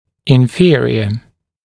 [ɪn’fɪərɪə][ин’фиэриэ]нижний, занимающий более низкое положение в теле человека особенно по отношению к схожим органам